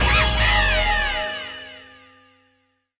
Mode Campaign Key Sound Effect
Download a high-quality mode campaign key sound effect.